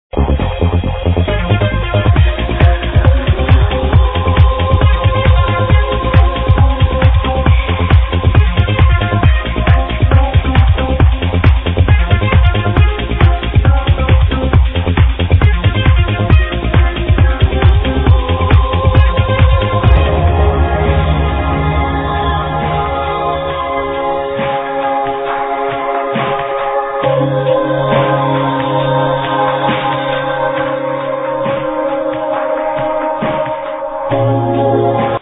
Deep, Euphoric tune.....Help ID
this tune does rock, in a epic way!!